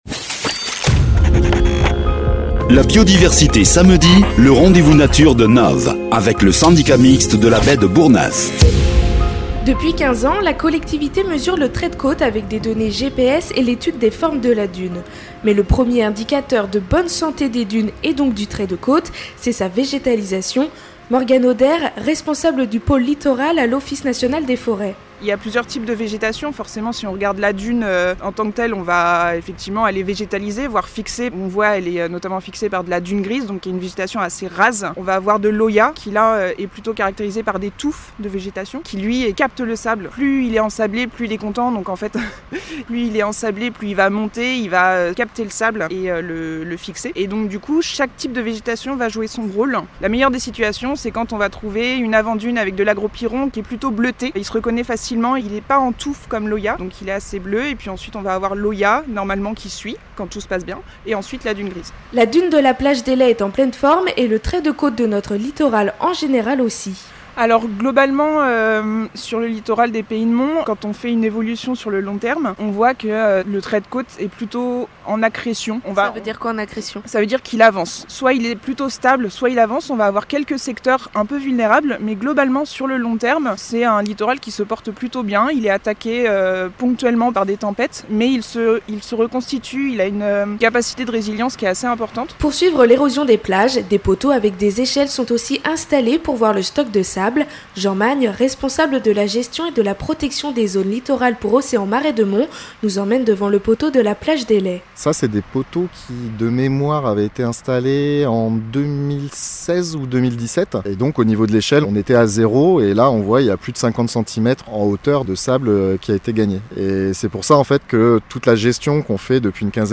En ce premier lundi de février, retrouvons un reportage, en partenariat avec le Syndicat mixte de la Baie de Bourgneuf.